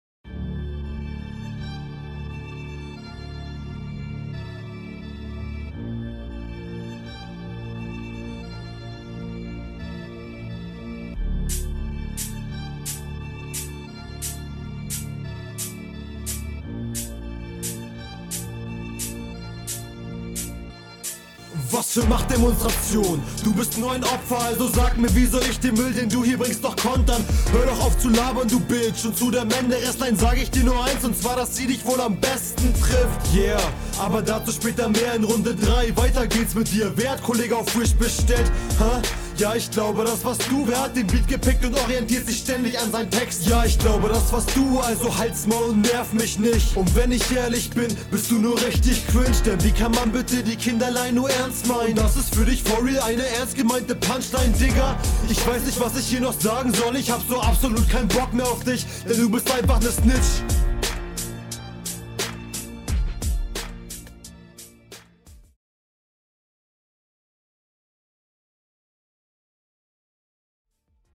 Finde deine Stimme kommt hier Nice dein Flow ist auch großteils Solide trotzdem hat der …